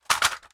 328d67128d Divergent / mods / Soundscape Overhaul / gamedata / sounds / material / large-weapon / collide / bounce02hl.ogg 8.6 KiB (Stored with Git LFS) Raw History
bounce02hl.ogg